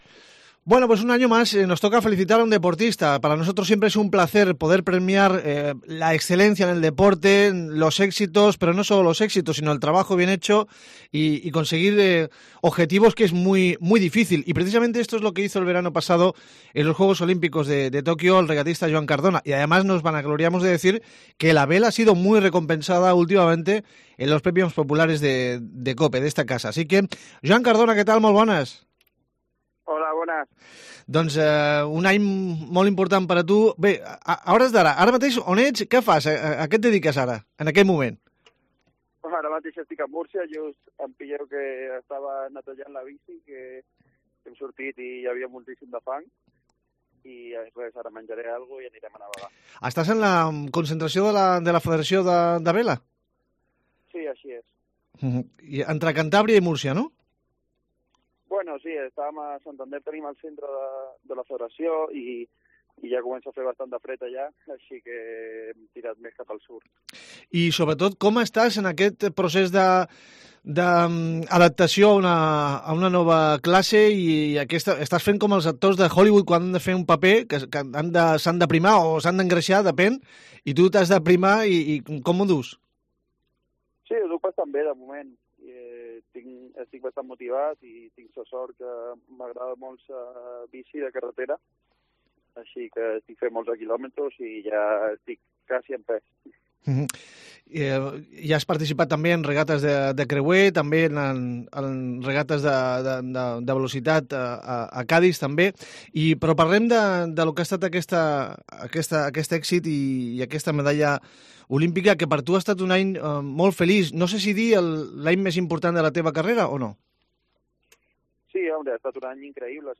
Hablamos con él de cómo fue el camino olímpico y lo que está por llegar.